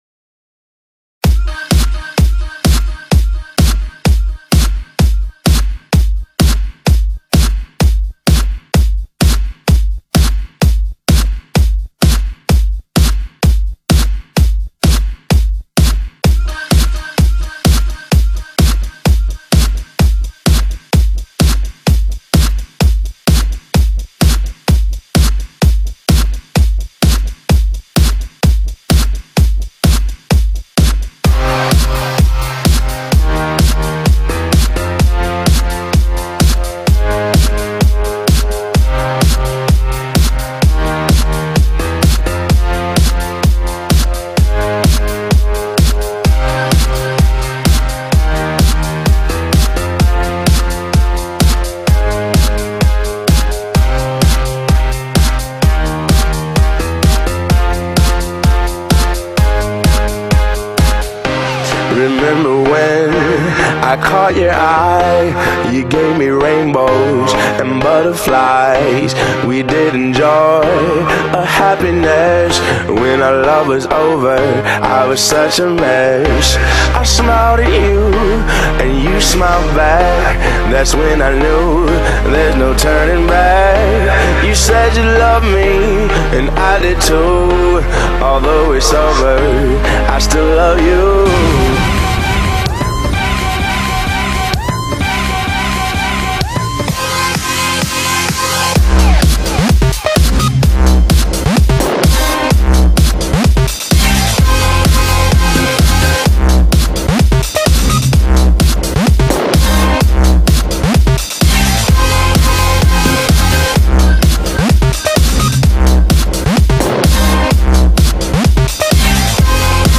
I love the smell of fresh electro in the morning.